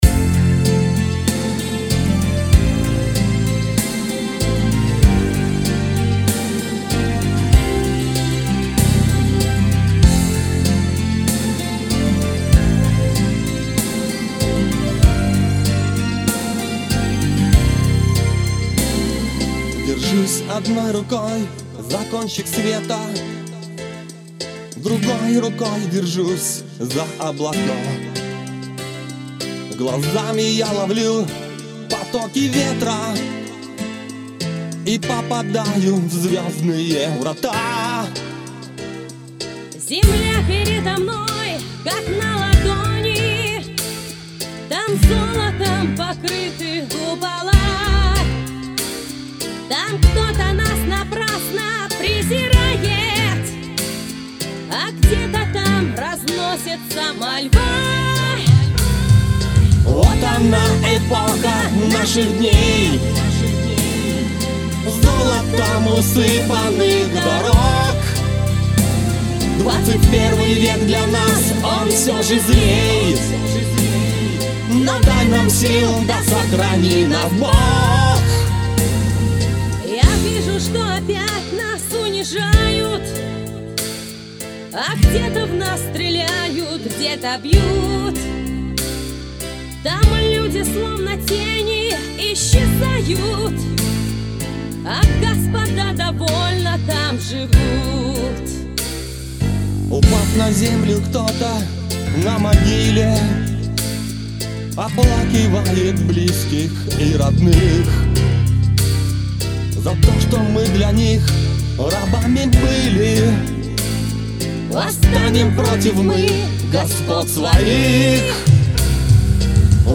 Поп Рок